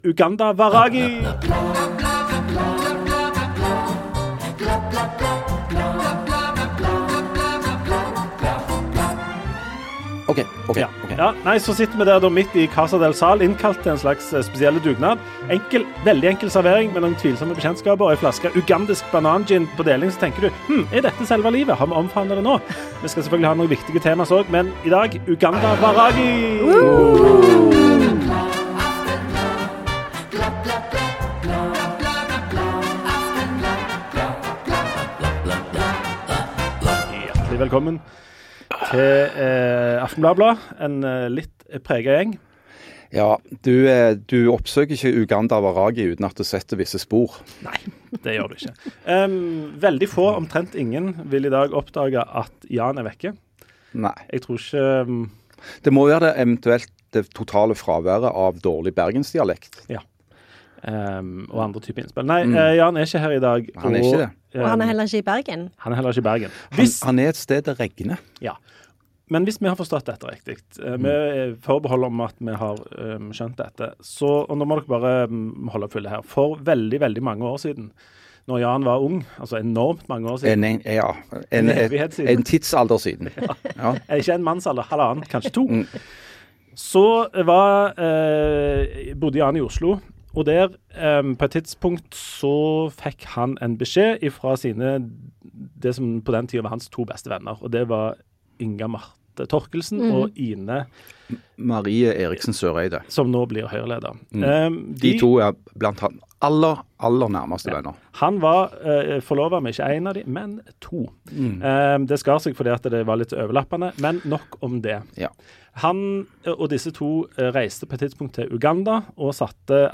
Ukesaktuelt nyhetsmagasin med lause snipp.